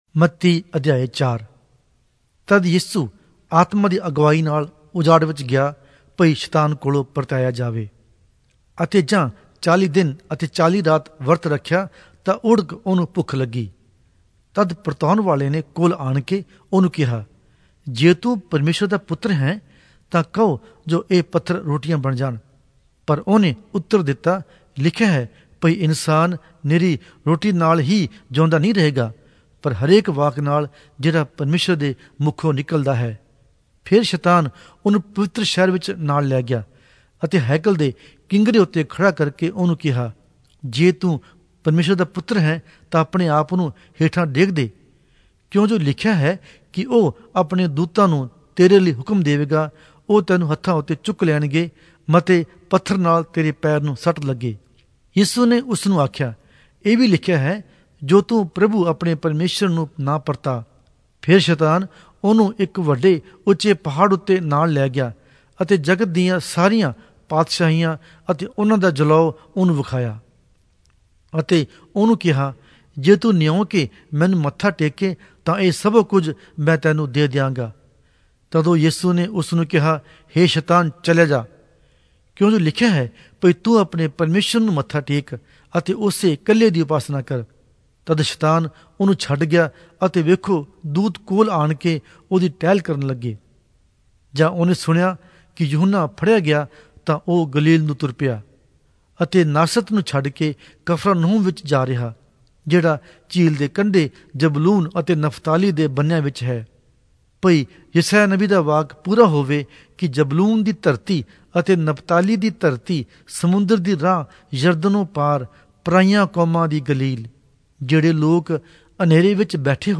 Punjabi Audio Bible - Matthew 15 in Rv bible version